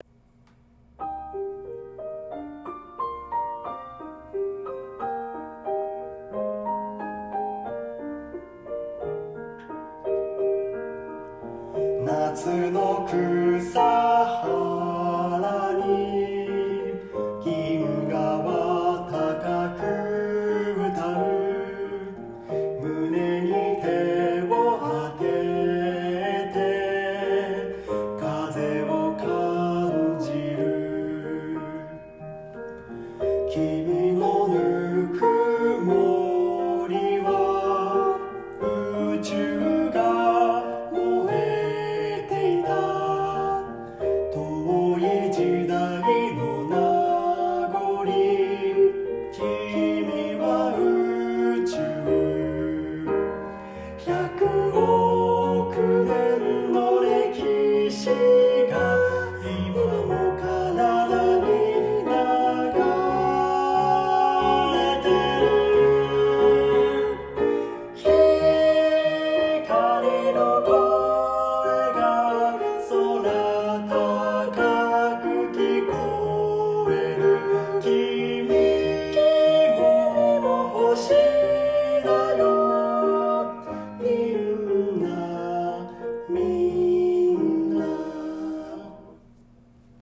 （合唱曲）を今すぐダウンロード＆リピート再生できます！